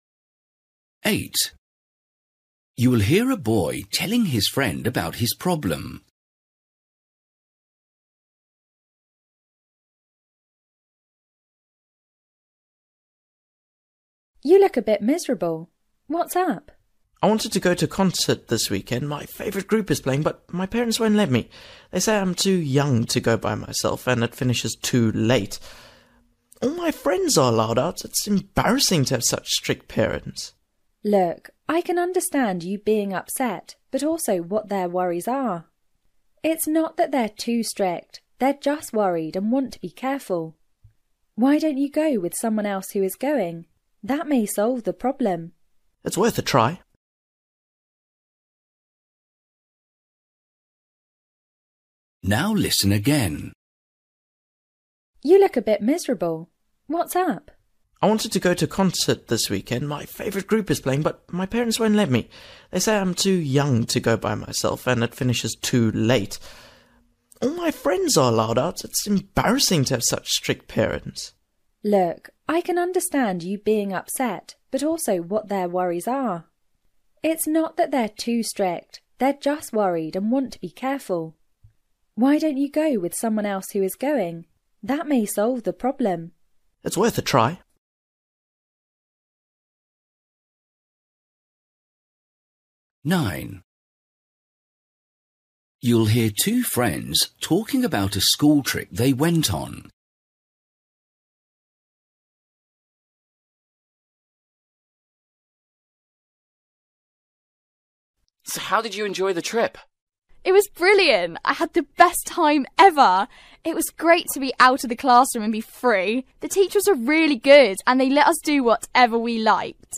Listening: everyday short conversations
8   You will hear a boy telling his friend about his problem. The girl advises the boy
9   You will hear two friends talking about a school trip they went on. What did the boy like best about it?
11   You will hear two friends talking about an invitation. How does the girl feel about it?